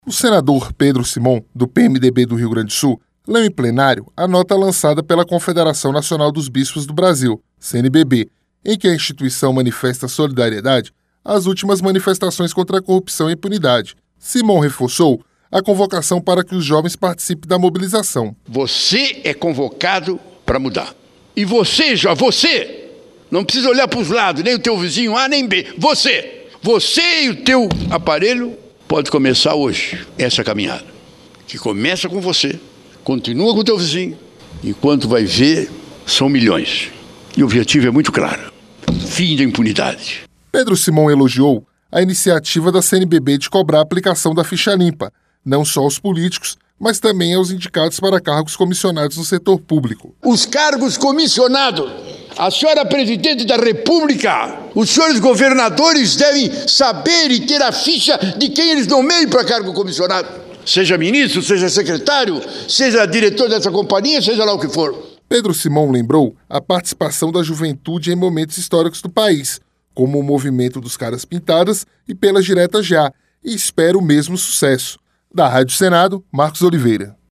O Senador Pedro Simon, do PMDB do Rio Grande do Sul, leu em Plenário a nota lançada pela Confederação Nacional dos Bispos do Brasil, CNBB, em que a instituição manifesta solidariedade às últimas manifestações contra a corrupção e a impunidade. Simon reforçou a convocação para que os jovens participem da mobilização.